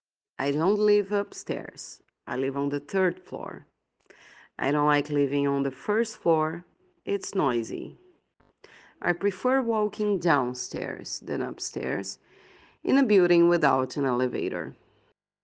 PRONUNCIATION 🗣